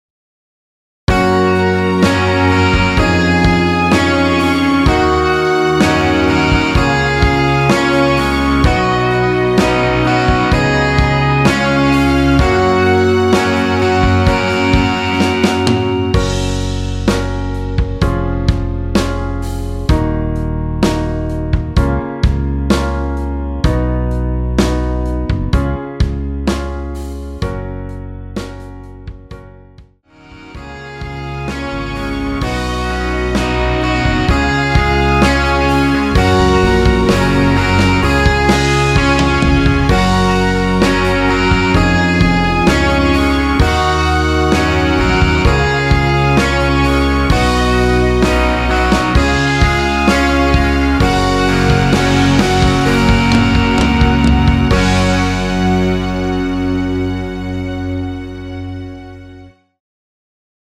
원키에서(-1)내린 MR입니다.
F#
앞부분30초, 뒷부분30초씩 편집해서 올려 드리고 있습니다.